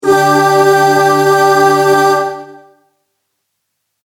| voices effect |